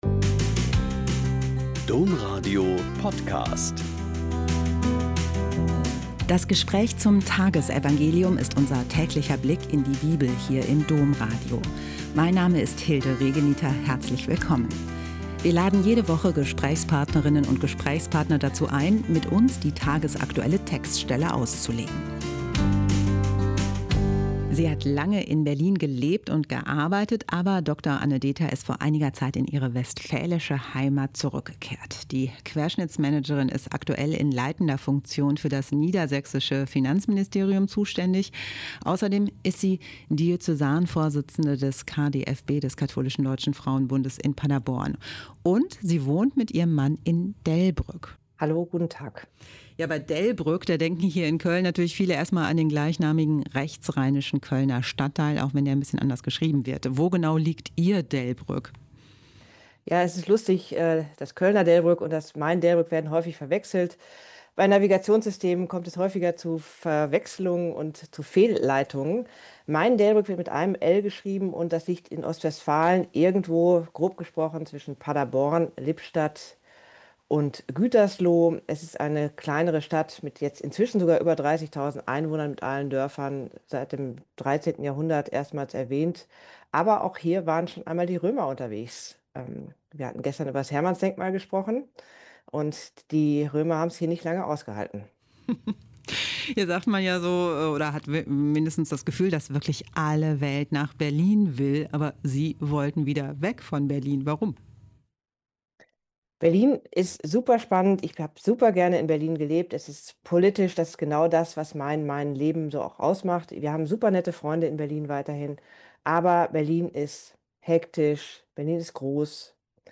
Mt 11,28-30 - Gespräch